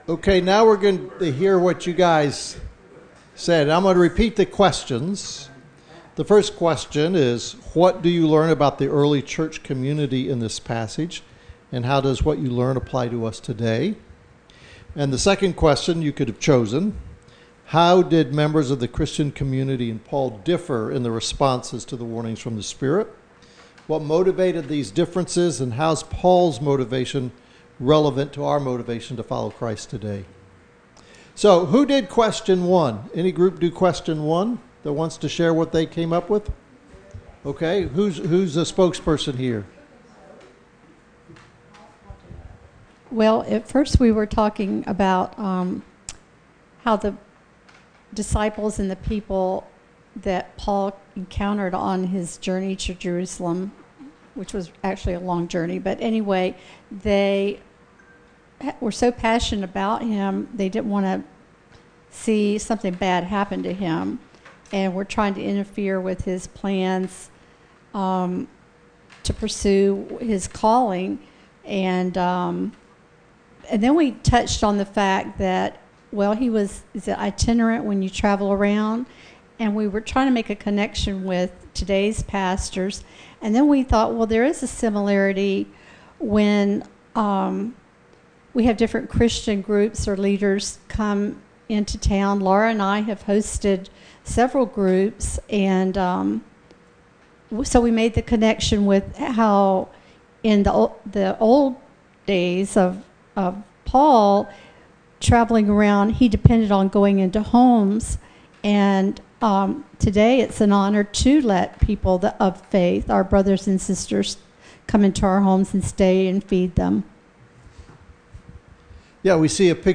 Acts 21:1-16 Service Type: Gathering The Apostle Paul was willing to follow Jesus